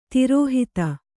♪ tirōhita